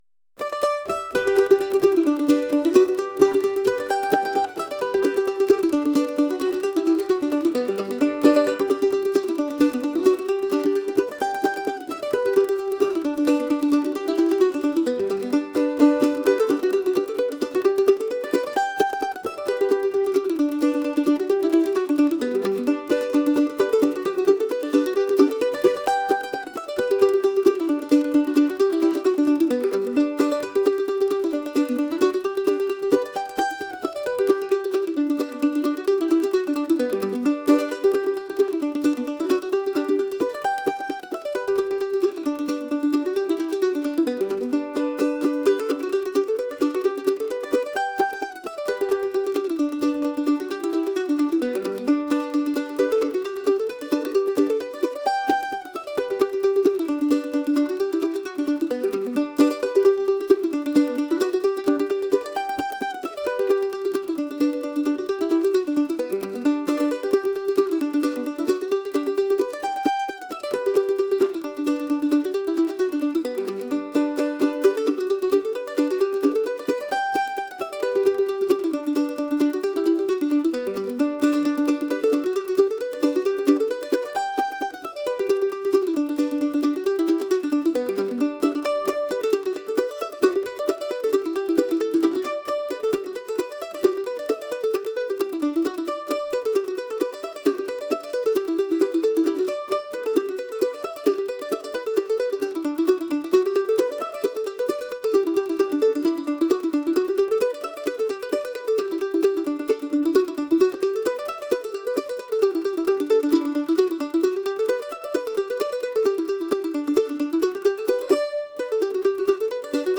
traditional | folk